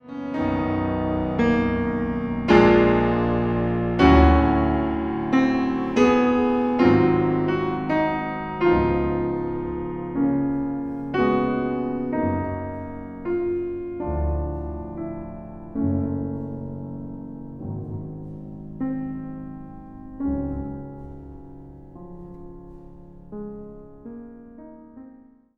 Instrumentaal | Piano
piano